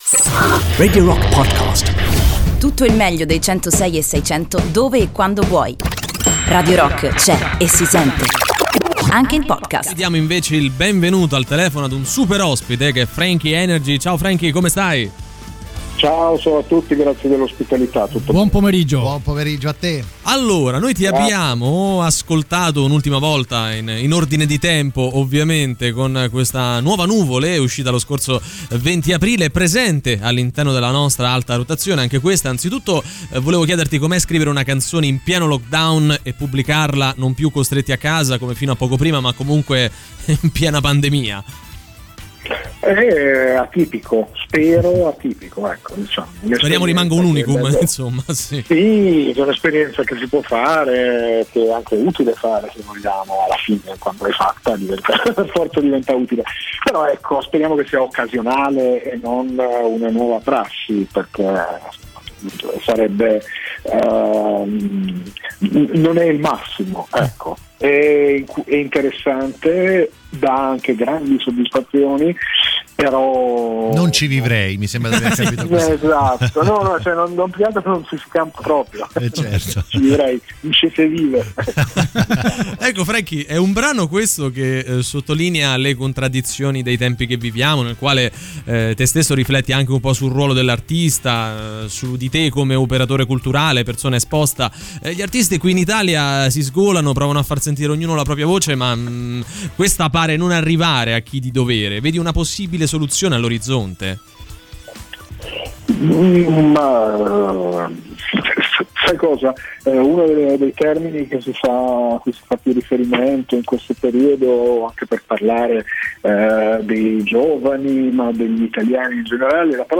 Interviste: Frankie HI-NRG MC (01-02-21)